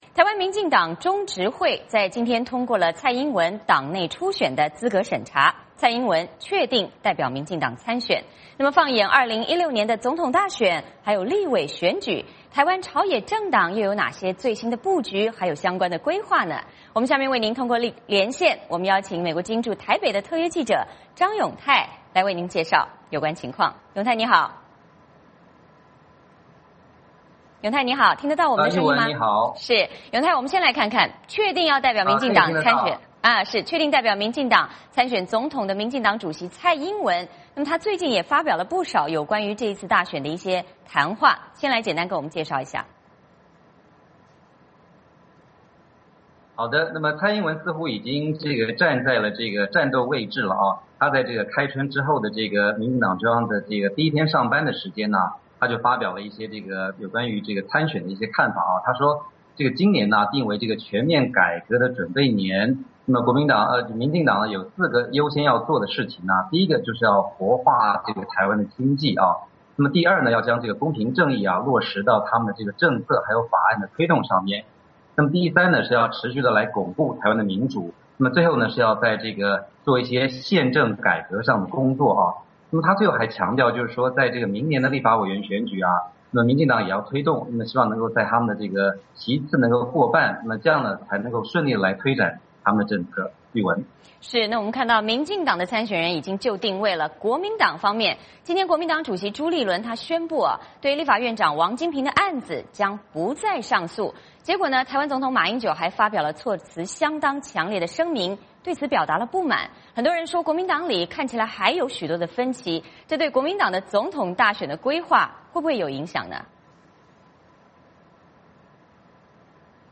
嵌入 VOA连线：台湾朝野政党2016总统大选和立委选举最新布局 嵌入 代码已经复制到剪贴板。